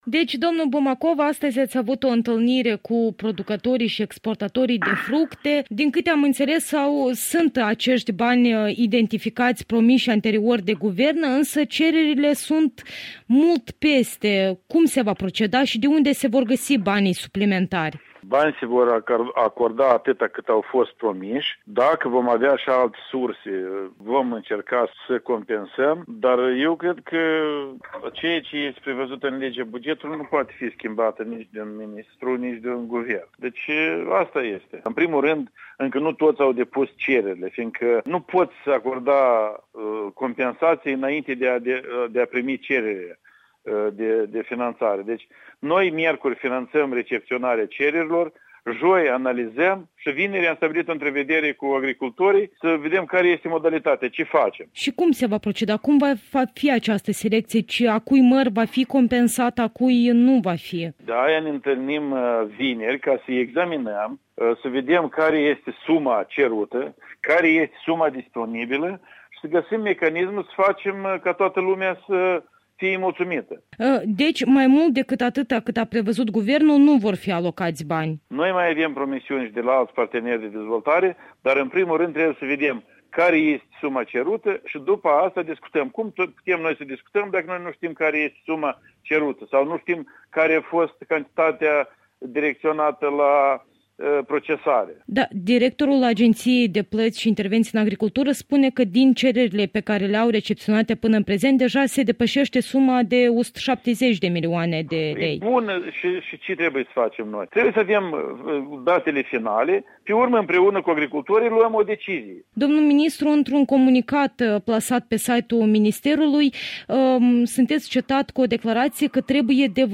Interviu cu ministrul agriculturii Vasile Bumacov